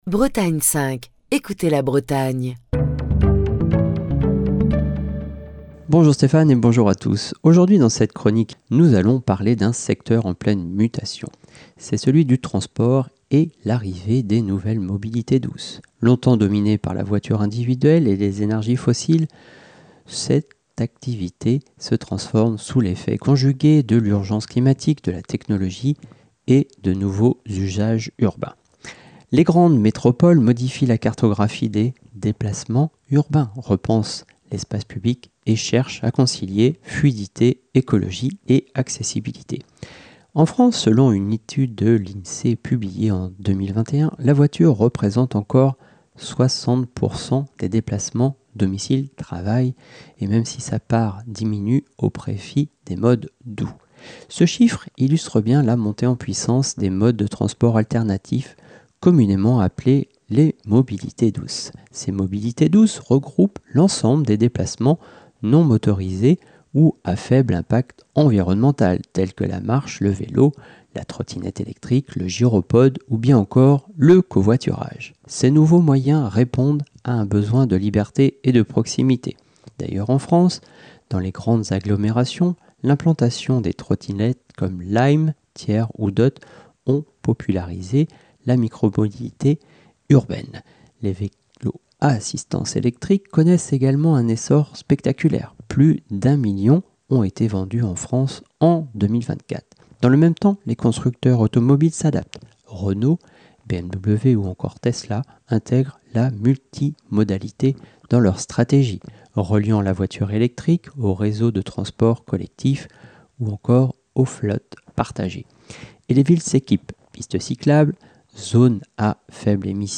Chronique du 14 novembre 2025.